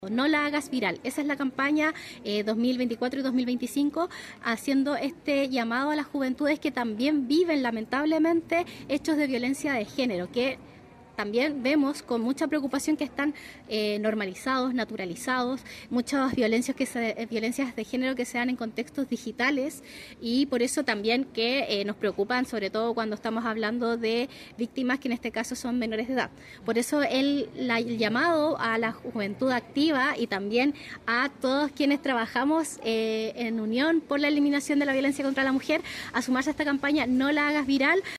La iniciativa, que fue presentada en el Liceo Polivalente Experimental de Concepción, busca generar consciencia en los jóvenes sobre la violencia de género e informar de los respectivos mecanismos de denuncia.
En la instancia, Camila Contreras, seremi de la Mujer y Equidad de Género del Biobío, detalló la campaña y mencionó su énfasis en educar sobre la prevención de agresiones digitales.